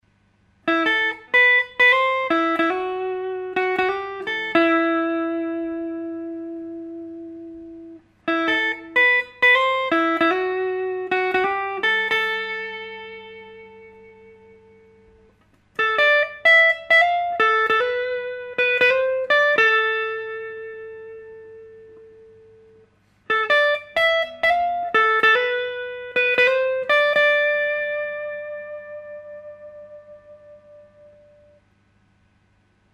He plays an old nice Les Paul Special with Lindy Fralin AlNiCo pickups.
He has a Rambler Combo with Power Scaling & a Celestion Century Vintage speaker.
The recordings are made with an AT 4300 condenser microphone and